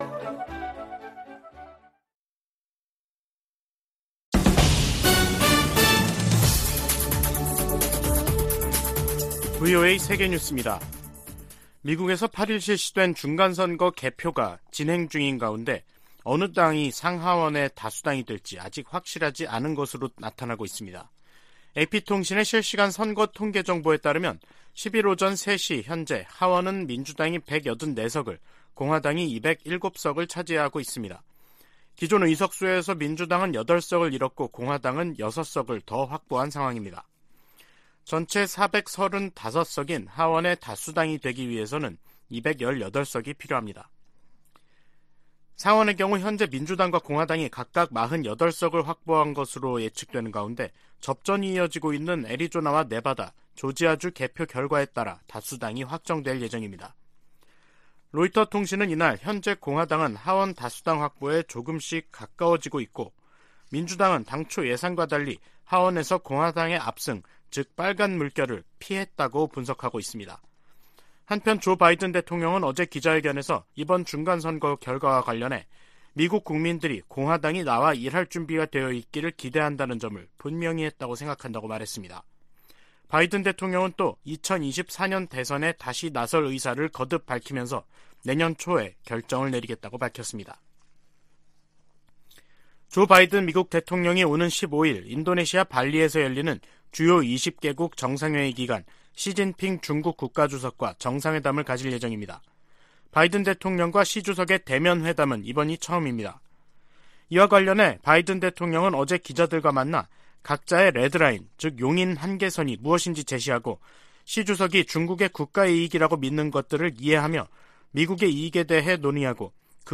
VOA 한국어 간판 뉴스 프로그램 '뉴스 투데이', 2022년 11월 10일 2부 방송입니다. 미국 중간선거 결과 의회 다수당의 변화가 예상되는 가운데 한반도 등 대외 현안들에 어떤 영향을 미칠지 주목됩니다. 미국과 한국, 일본, 중국의 정상들이 G20 정상회의에 참석하면서 북한 문제를 둘러싼 다자 외교전이 펼쳐질 전망입니다. 북한이 핵물질 생산을 위해 영변 핵시설을 지속적으로 가동하고 있다고 전 국제원자력기구(IAEA) 사무차장이 밝혔습니다.